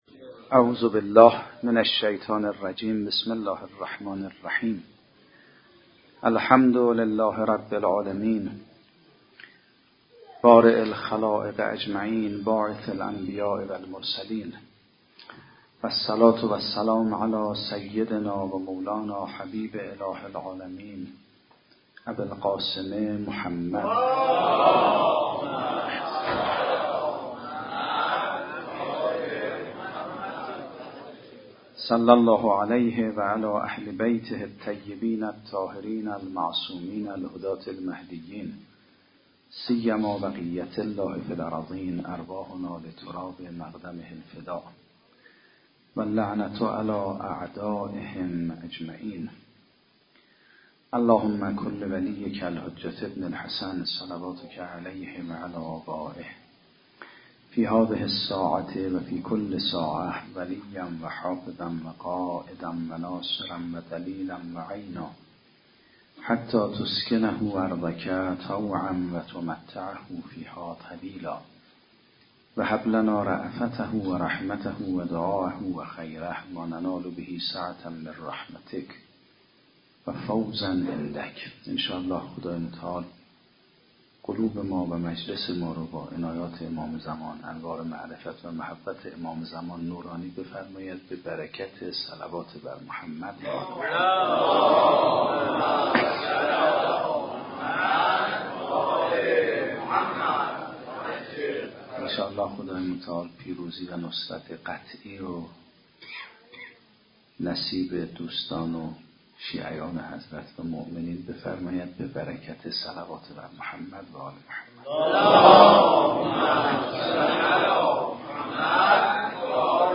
گروه معارف - رجانیوز: متن زیر سخنرانی جناب آیت الله میرباقری در شب سوم ایام فاطمیه دوم سال 93 (ایام ابتدایی سال 94) برگزار شده است . آیت الله میرباقری در این جلسه بیان می کند: برای ذکر کثیر مصادیقی ذکر شده که یکی از آنها قرآن است.
سخنرانی آیت الله میرباقری- فاطمیه 94.mp3